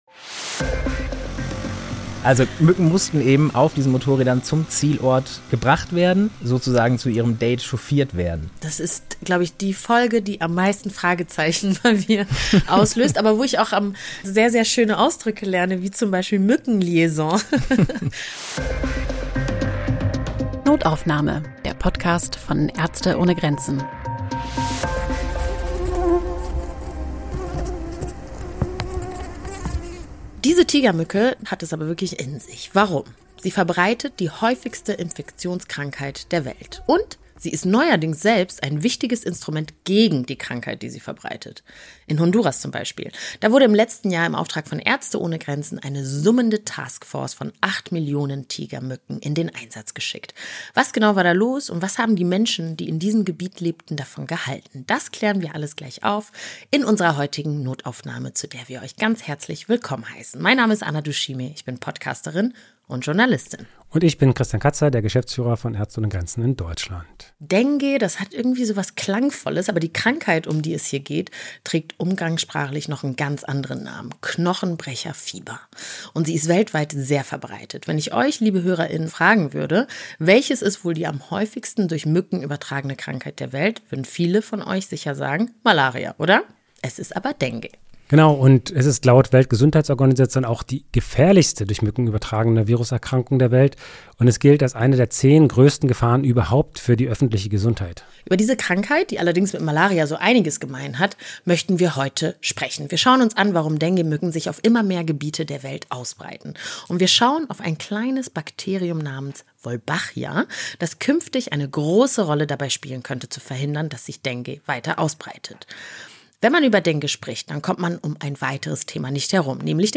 Genres: Health & Fitness, Medicine, Places & Travel, Society & Culture